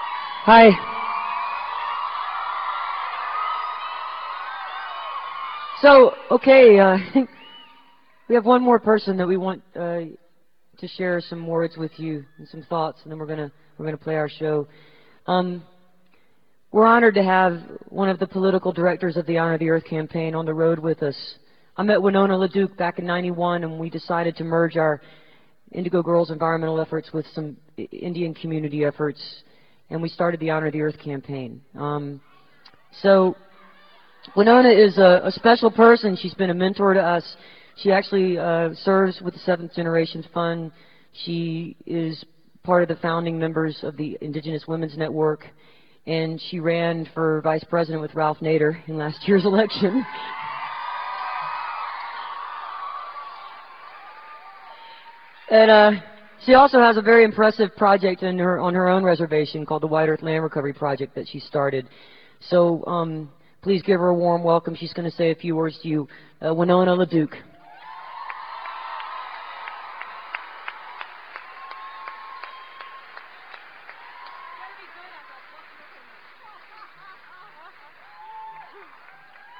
lifeblood: bootlegs: 1997-10-02: adams field house - missoula, montana
04. winona laduke introduction (emily and amy) (1:19)